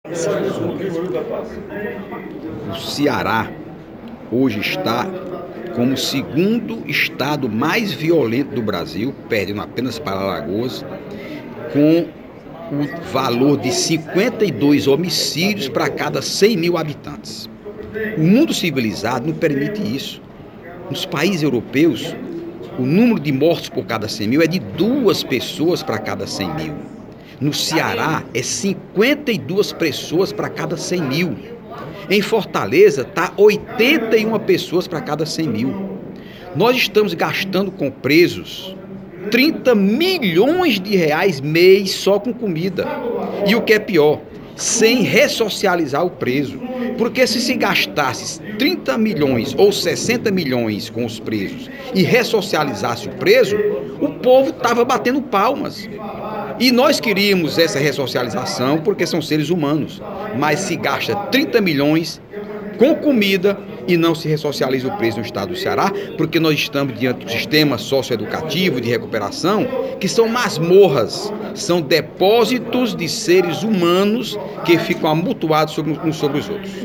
O deputado Heitor Férrer (PSB) cobrou, nesta quinta-feira (22/06), durante o primeiro expediente da sessão plenária, políticas públicas que possam quebrar fatores que geram a violência no Brasil, sobretudo no Ceará.